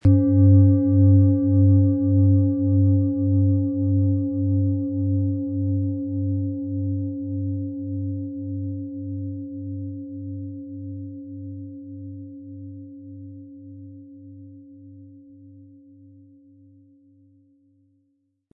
Planetenschale® Offen und Weit fühlen & Erde Dich mit OM-Ton & Tageston, Ø 24,5 cm, 1300-1400 Gramm inkl. Klöppel
• Tiefster Ton: Tageston
PlanetentöneOM Ton & Tageston & DNA (Höchster Ton)
MaterialBronze